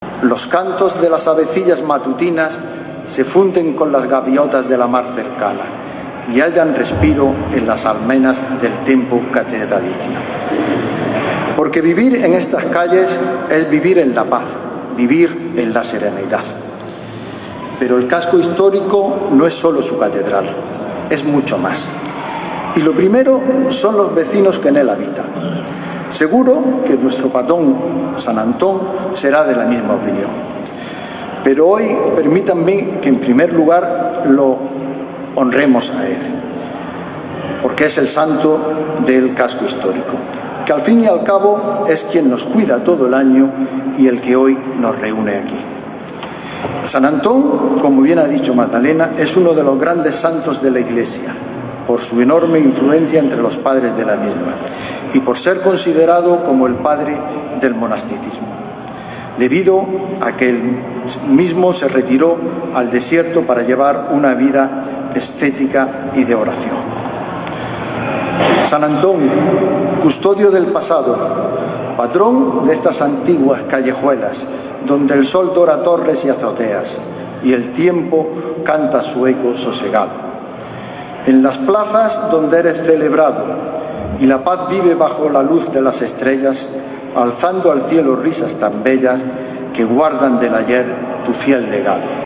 El pregonero, Luis Columna, presidente de la Audiencia Provincial de Almería, ha rememorado su infancia en la carpintería de su abuelo materno, ubicada en la actual calle José Ángel Valente
En la tarde de ayer, acompañada por los concejales Óscar Bleda y Eloísa Cabrera, la regidora acudió a la Iglesia de San Juan Evangelista donde el presidente de la Audiencia Provincial de Almería, Luis Columna, pronunció el pregón que da inicio a estos días tan especiales.
TOTAL-LUIS-MIGUEL-COLUMNA-PREGON-SAN-ANTON.wav